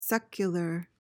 PRONUNCIATION:
(SEK-yuh-luhr)